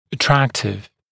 [ə’træktɪv][э’трэктив]привлекательный, приятный